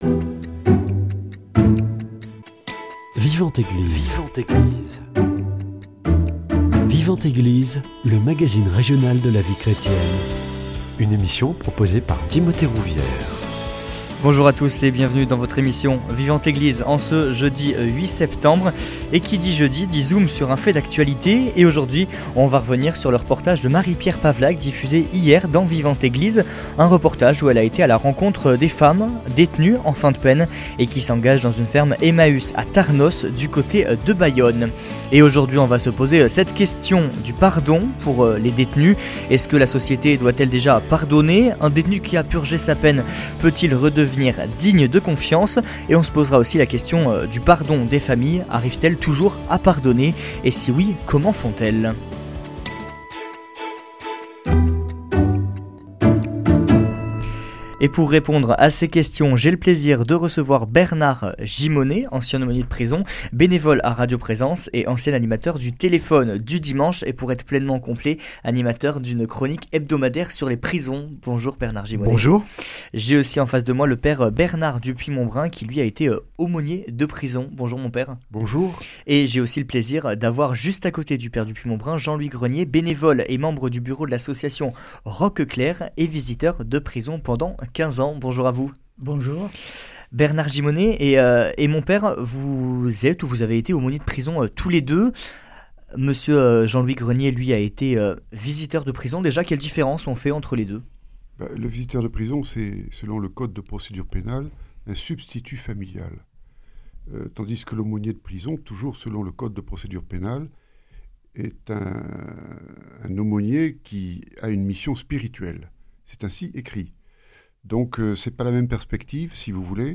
visiteur de prison
aumôniers de prison.